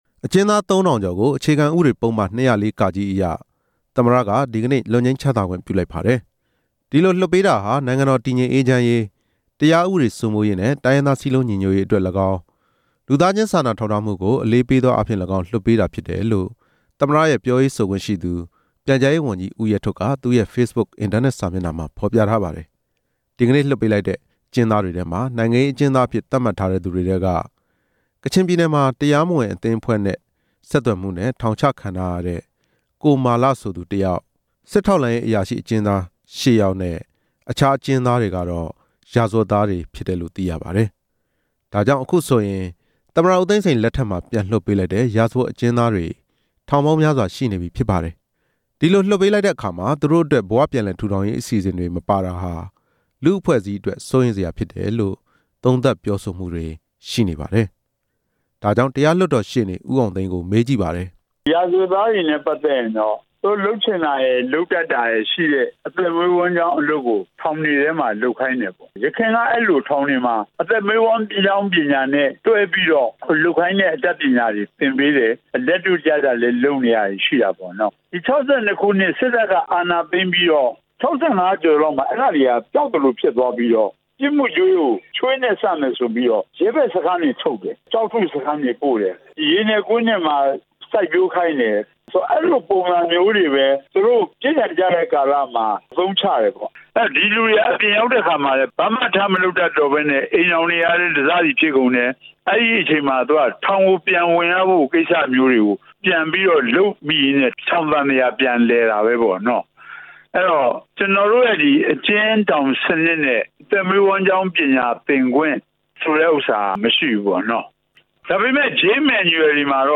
စုစည်းတင်ပြချက်